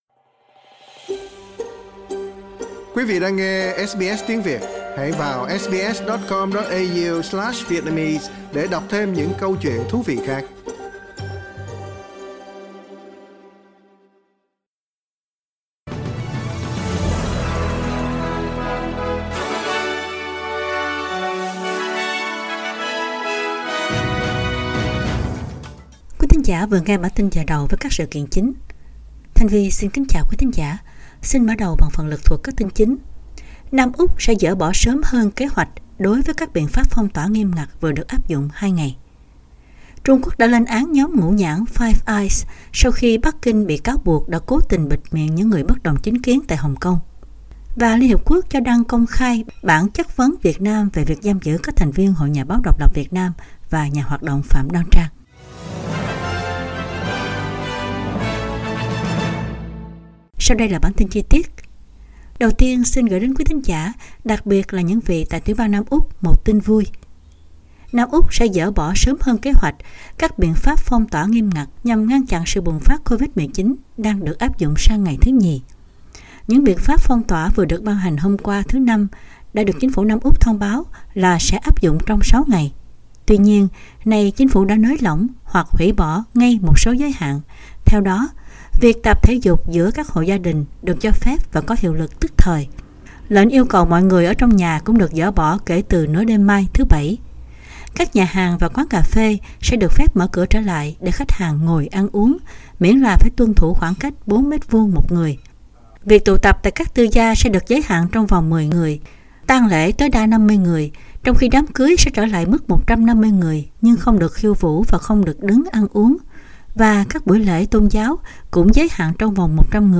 Bản tin chính trong ngày của SBS Radio.
Vietnamese news bulletin Source: Getty